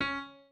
piano2_32.ogg